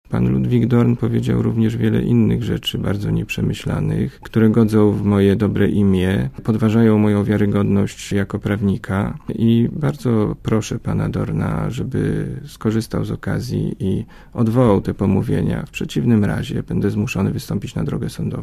Mówi Wojciech Brochwicz, gość Radia ZET